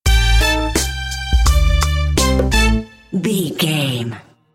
Short music, corporate logo or transition between images,
Epic / Action
Fast paced
In-crescendo
Uplifting
Ionian/Major
bright
cheerful/happy
industrial
powerful
driving
groovy
funky
synthesiser